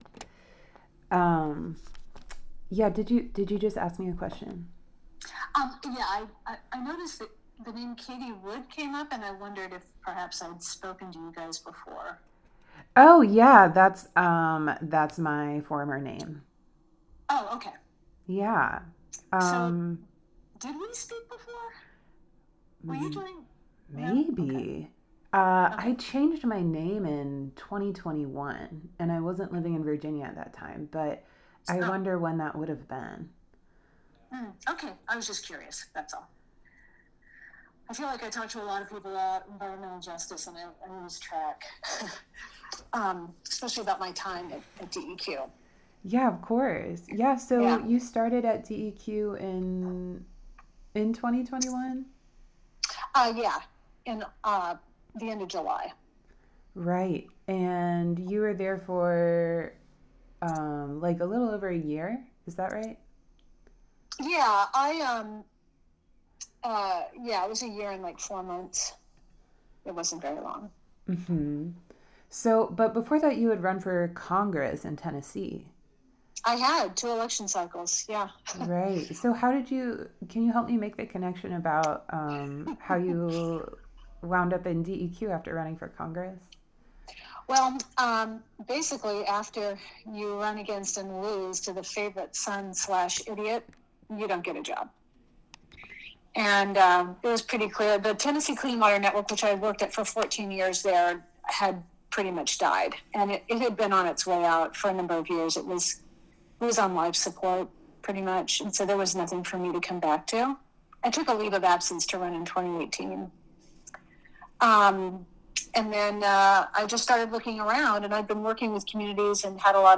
This is a phone interview with former Department of Environmental Quality
eq'ed and compressed